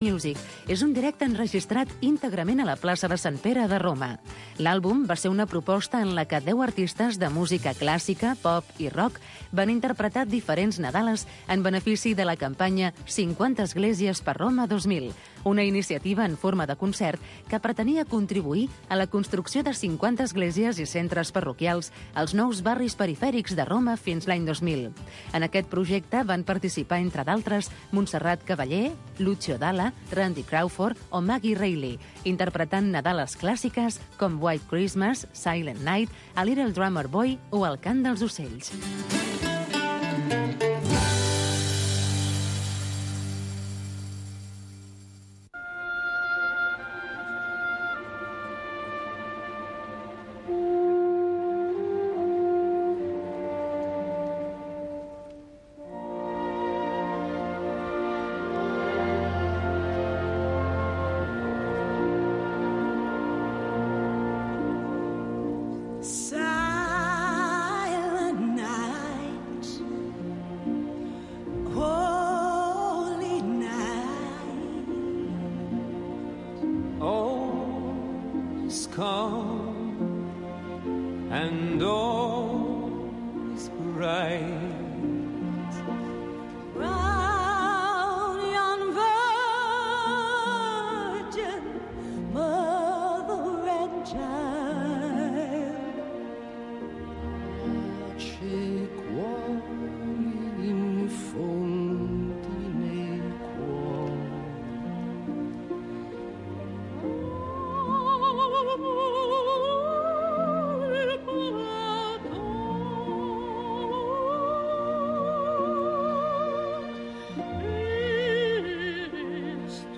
En concert. Repàs de concerts emblemàtics.